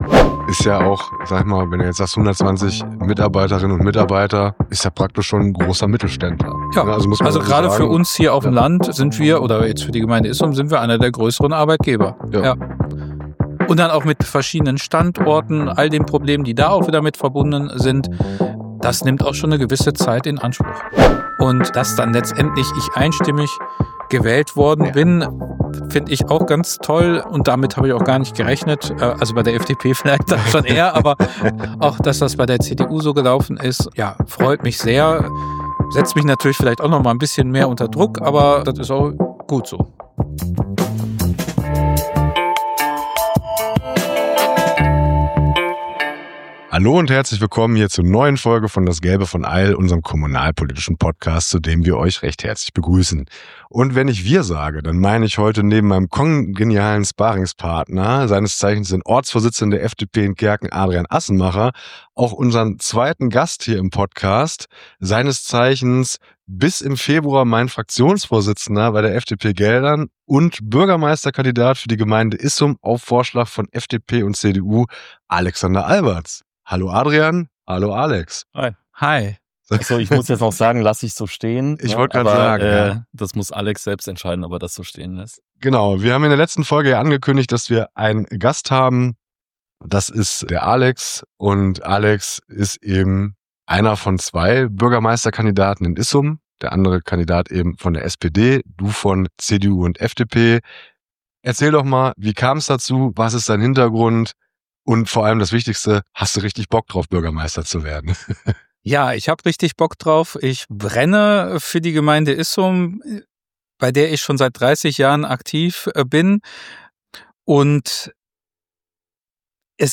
einen Gast am Mikrofon